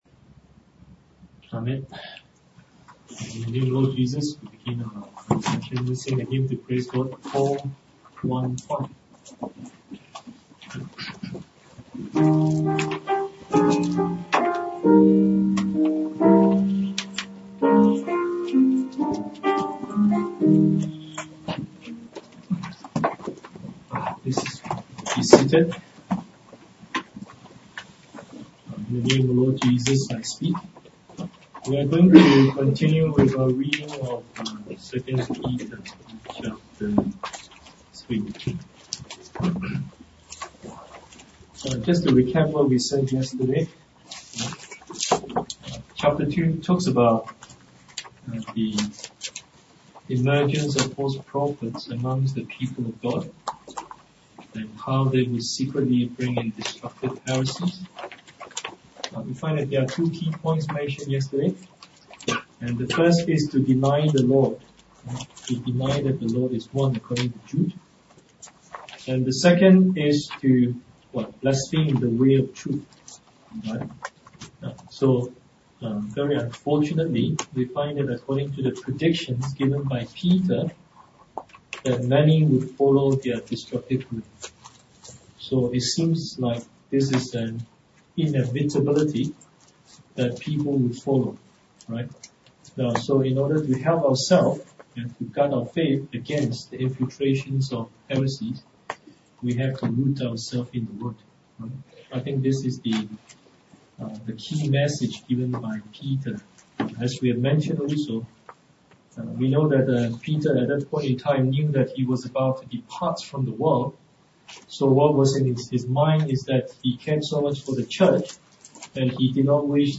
TJC True Jesus Church audio video sermons Truth Salvation Holy Spirit Baptism Foot Washing Holy Communion Sabbath One True God